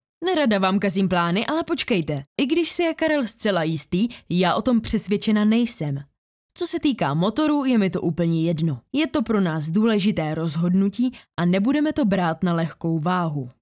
Ženský reklamní voiceover do jedné minuty
Natáčení probíhá v profesiálním dabingovém studiu.